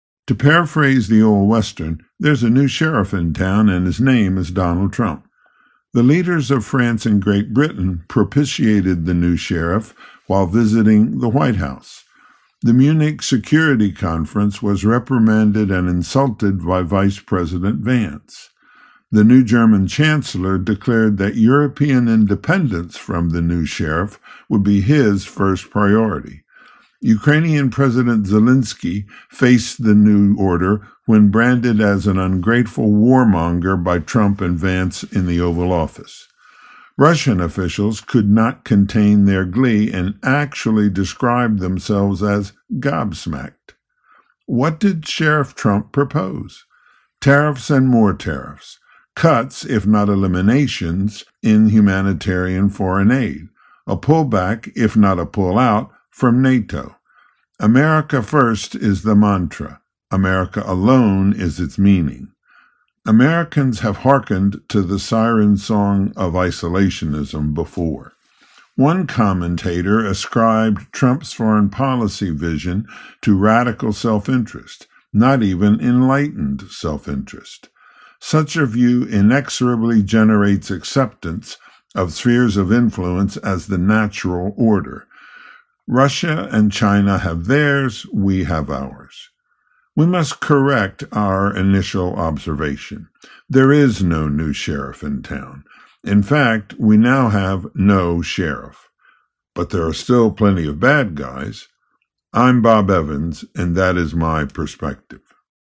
Perspectives are commentaries produced by and for WNIJ listeners, from a panel of regular contributors and guests.